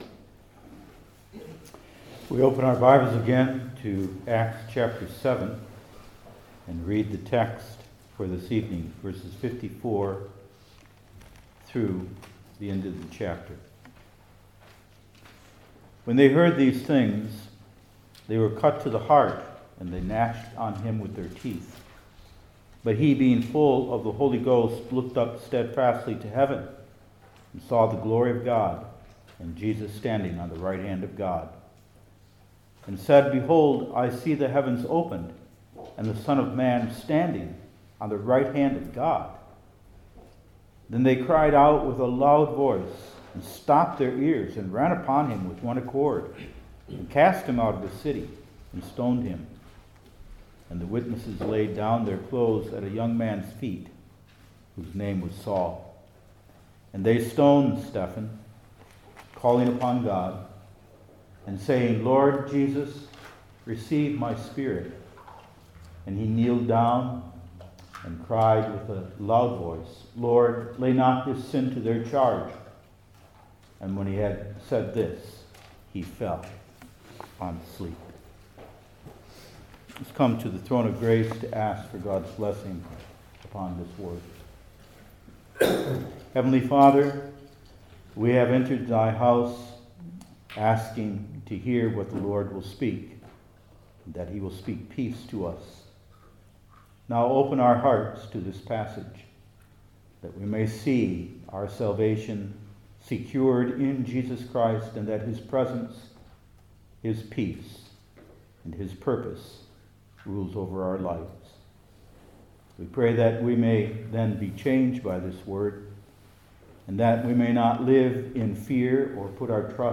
Acts 7:54-60 Service Type: New Testament Individual Sermons I. His Presence With Us II.